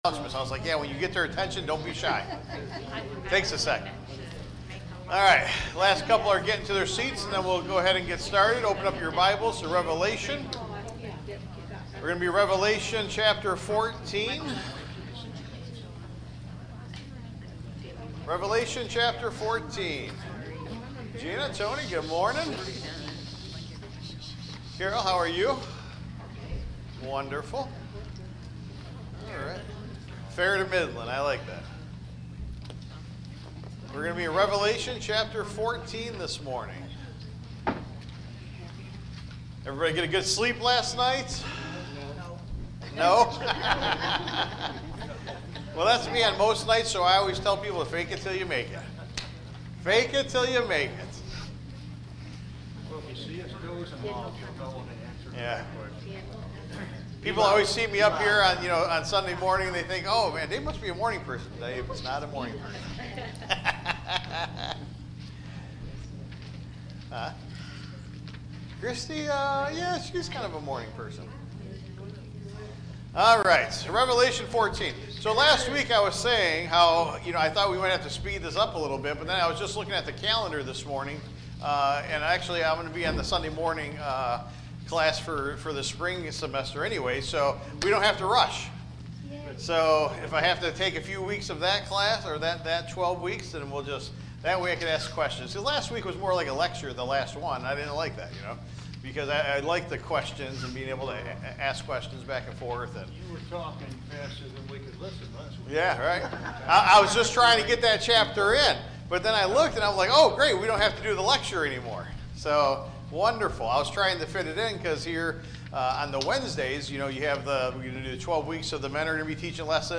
Taught live